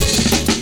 Track 14 - Drum Roll.wav